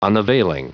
Prononciation du mot unavailing en anglais (fichier audio)
unavailing.wav